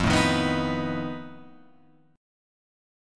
F7b5 chord